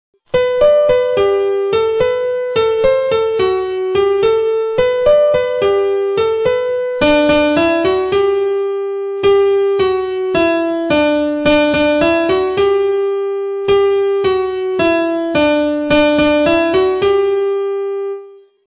We sing this song together at the start of every Rainbows session.